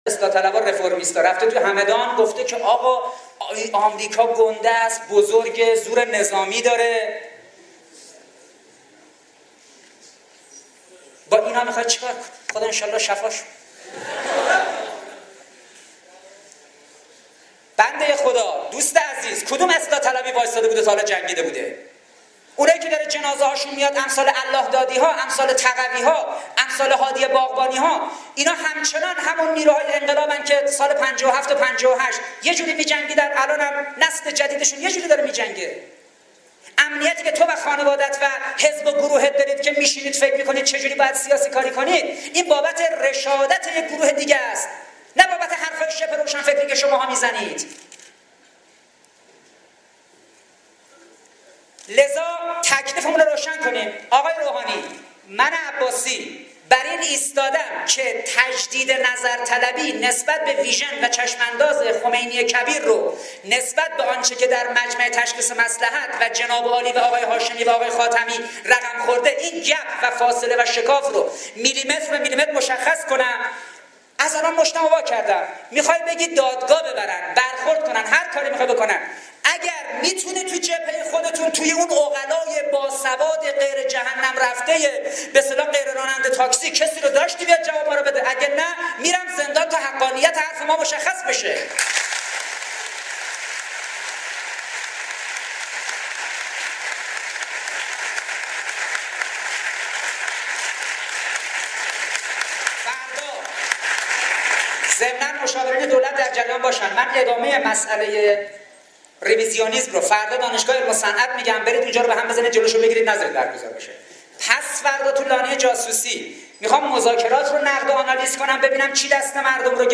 • پرشور, انقلابی, دکتر حسن عباسی, حسن روحانی, جنجالی